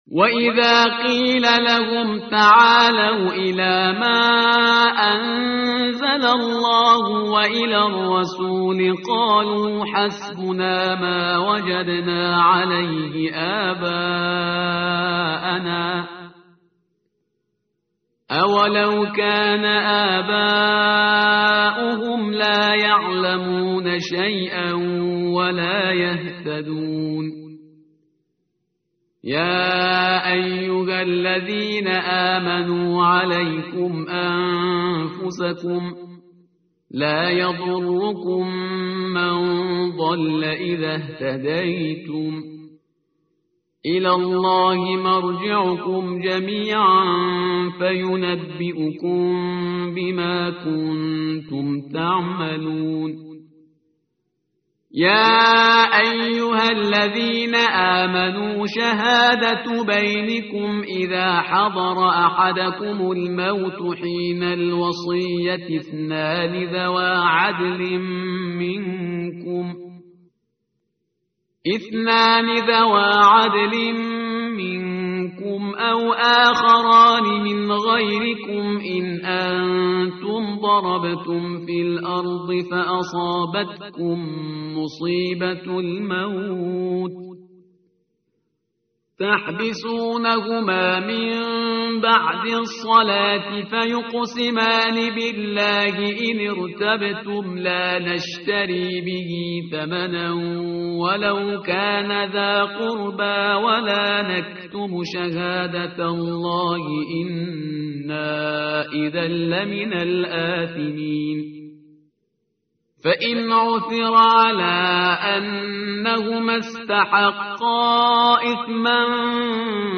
متن قرآن همراه باتلاوت قرآن و ترجمه
tartil_parhizgar_page_125.mp3